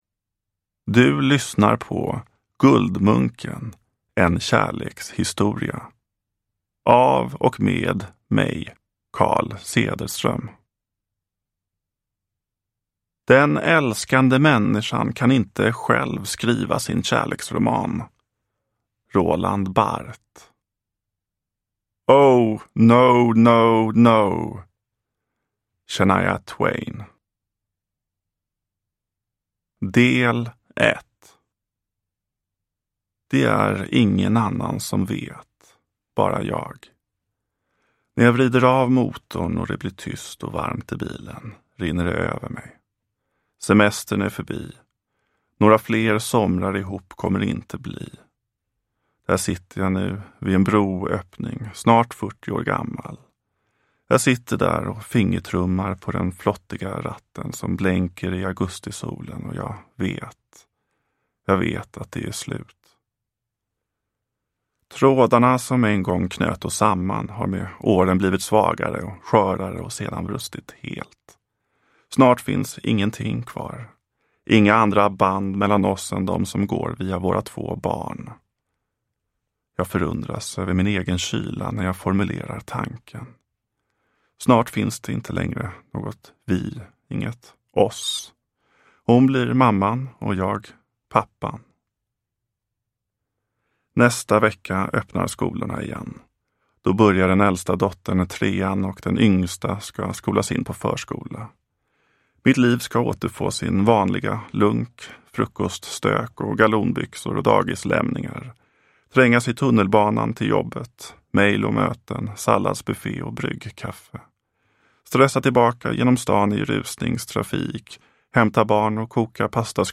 Guldmunken : en kärlekshistoria – Ljudbok – Laddas ner